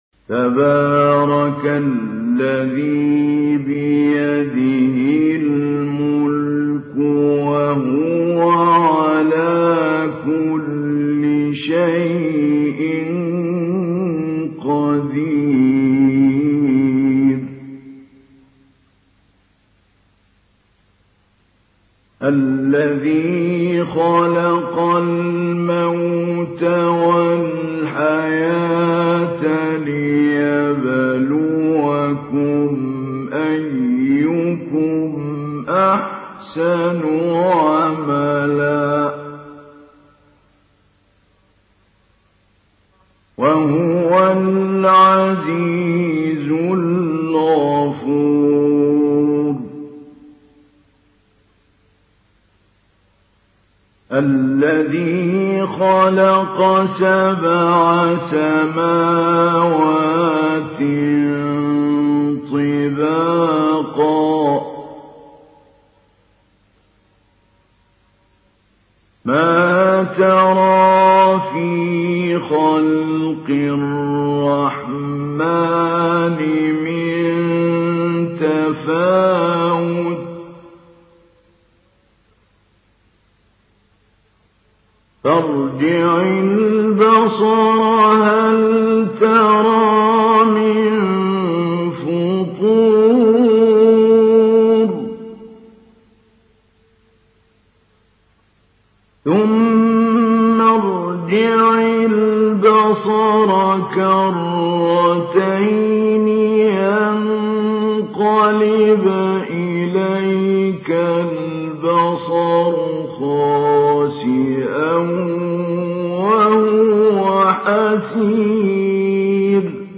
Surah আল-মুলক MP3 in the Voice of Mahmoud Ali Albanna Mujawwad in Hafs Narration
Surah আল-মুলক MP3 by Mahmoud Ali Albanna Mujawwad in Hafs An Asim narration.